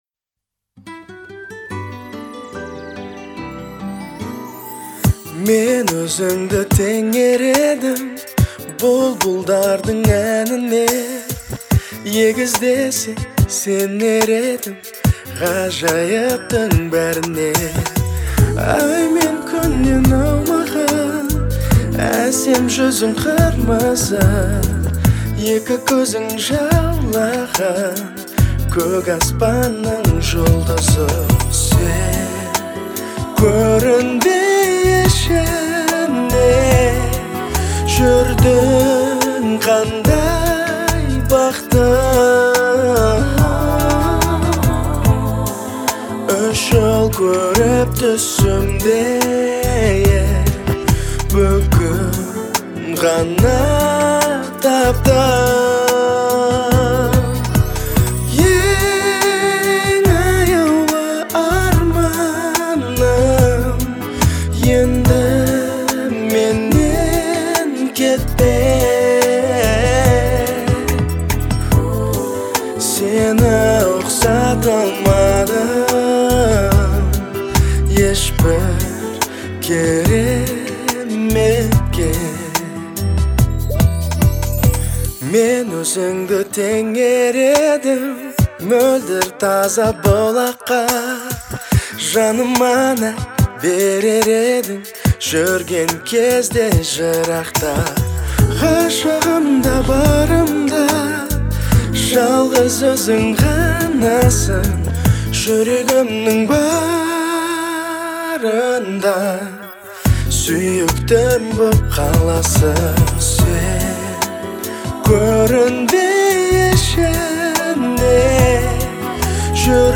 проникновенный вокал